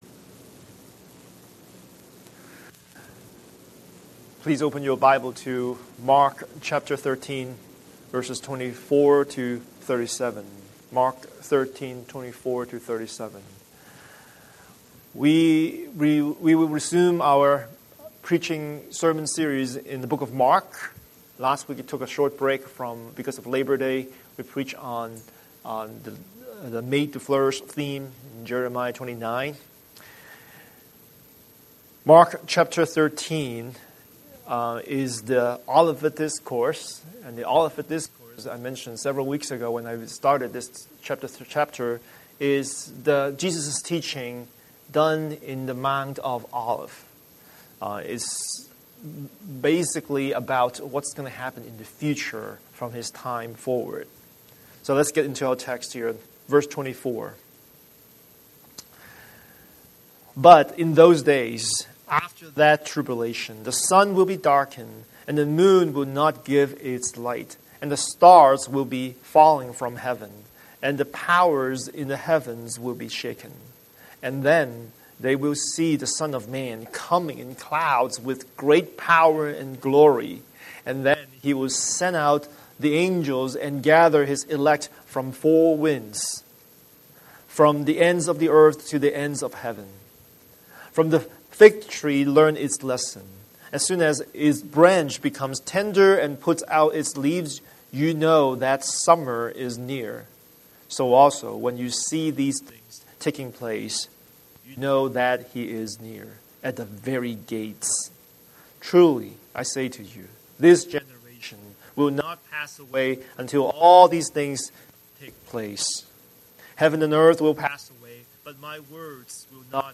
Scripture: Mark 13:24–37 Series: Sunday Sermon